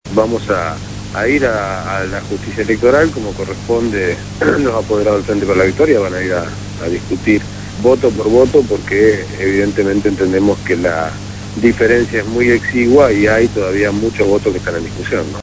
Escuchá la entrevista realizada el lunes 21 de marzo en el Programa «Punto de Partida» de Radio Gráfica